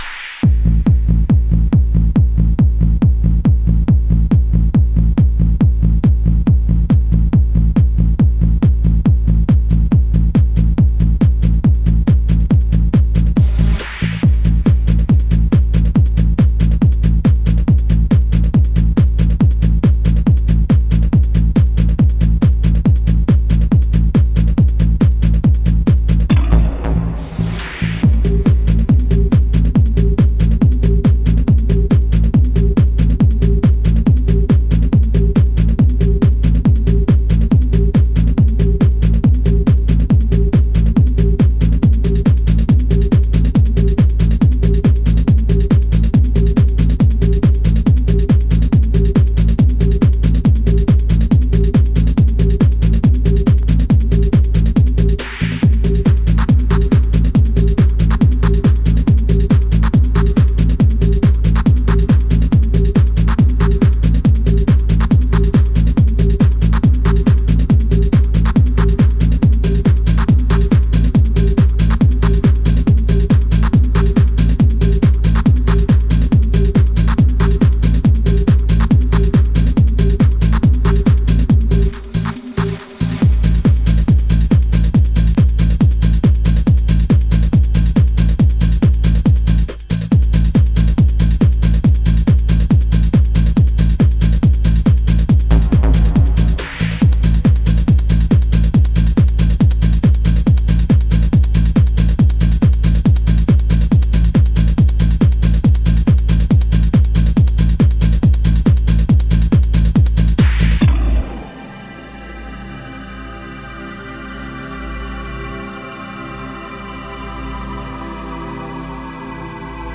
Genre: Club.